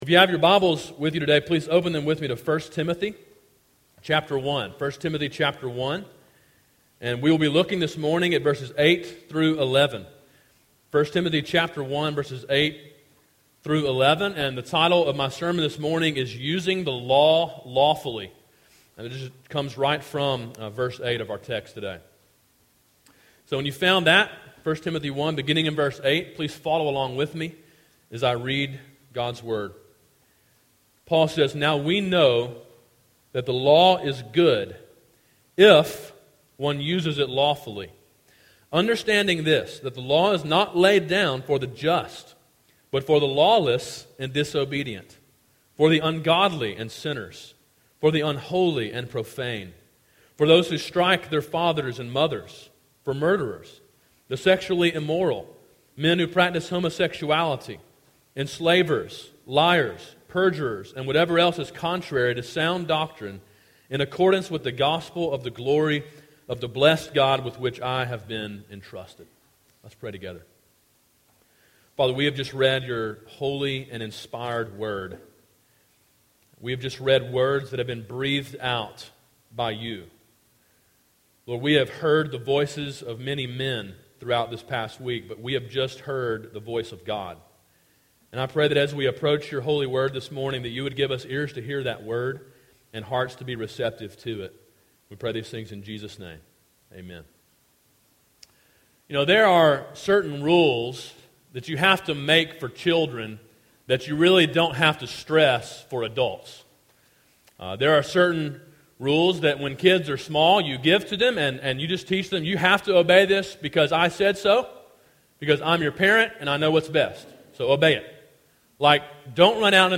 Sermon: “Using the Law Lawfully” (1 Timothy 1:8-11)
A sermon in a series on the book of 1 Timothy.